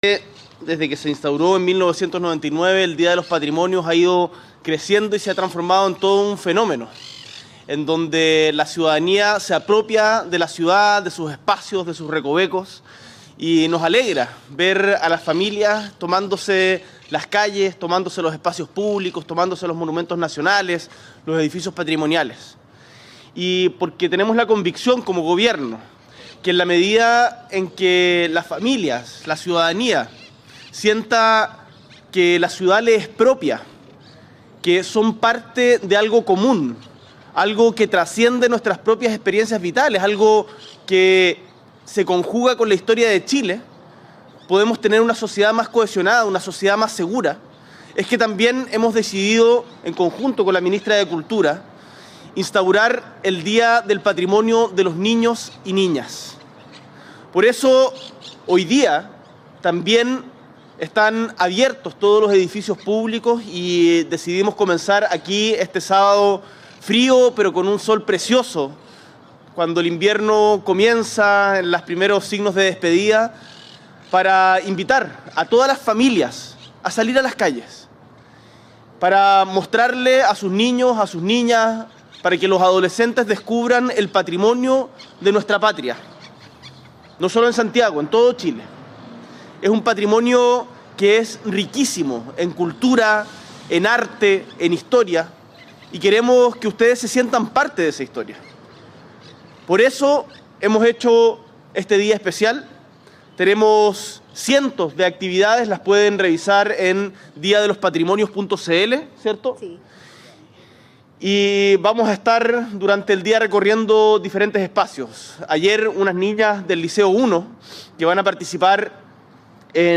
S.E. el Presidente de la República, Gabriel Boric Font, visita el Museo Artequin en el marco del Día de los Patrimonios de Niñas, Niños y Adolescentes